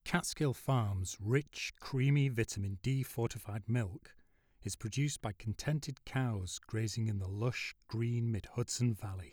There is a simple format issue. See where you produced two sound tracks (stereo) and only one of them has anything (wiggly blue lines) on it?
There is a tone behind you that doesn’t correspond to wall power or any other conventional source.
This is where we critically analyze the silent portion…except you didn’t submit one.